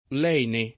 kU#ndo S1ffLa b0rea da kkU%lla gU#n©a ond $ ppLu ll$no] (Dante) — lene, forma dòtta, con -e- aperta da sempre; leno, forma pop. (lat. lenis con -e- lunga), attestata pure con -e- aperta dal ’500, ma certo in origine con -e- chiusa — cfr. allenare